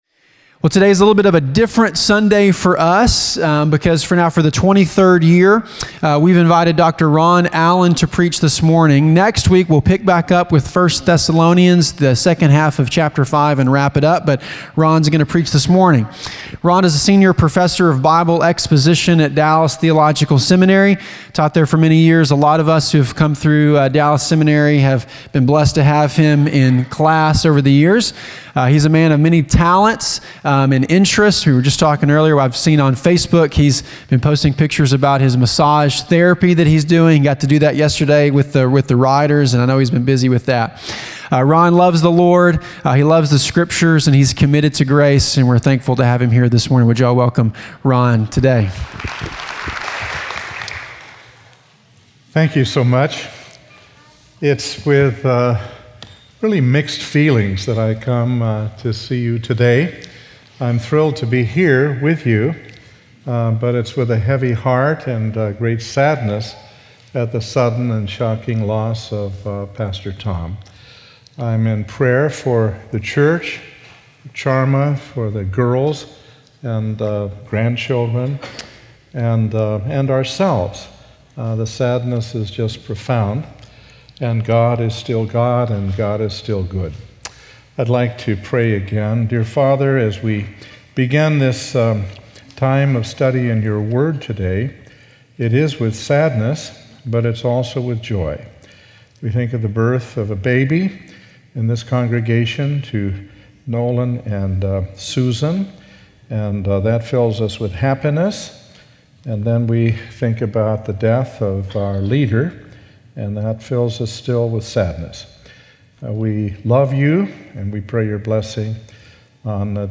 Message
Sunday morning messages from invited guests which are not part of our regular teaching series.